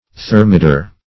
Thermidor \Ther`mi`dor"\, n. [F., fr. Gr. ? warm, hot.]